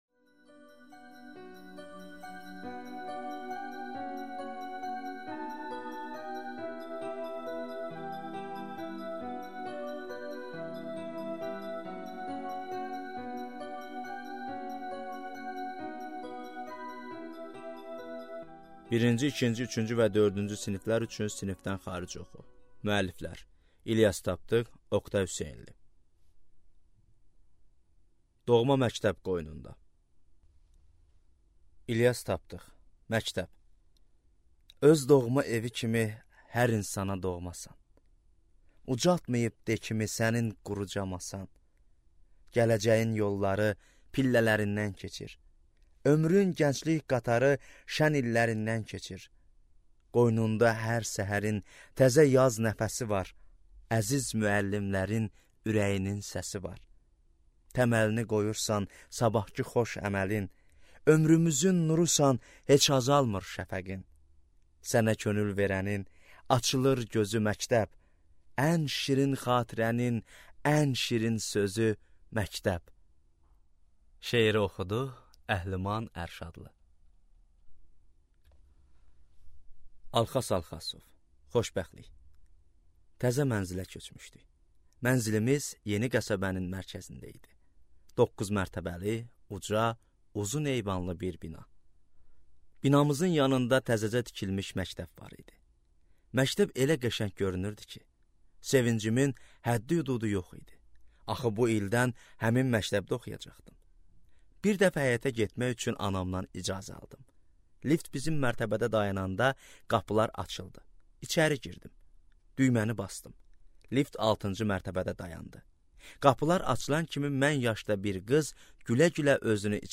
Аудиокнига I - IV siniflər üçün sinifdənxaric oxu | Библиотека аудиокниг